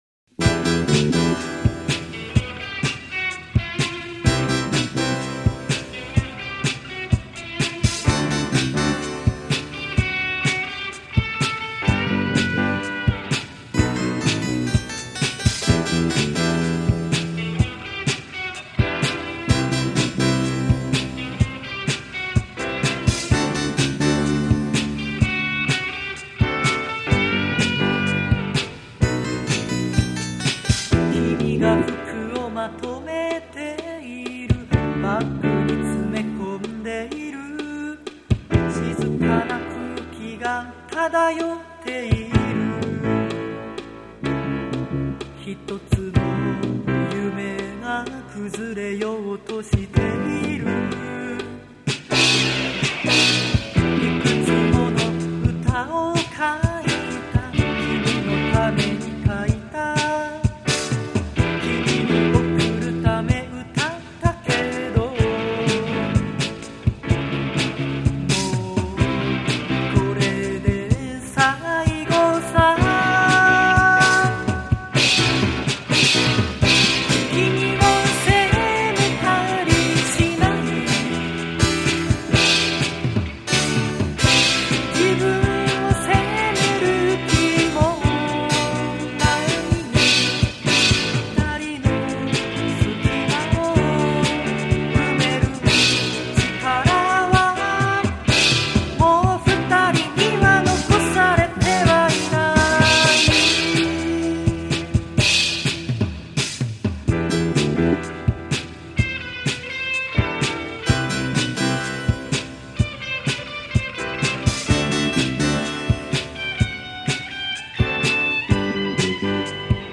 ボーカル・キーボード
ギター・ベース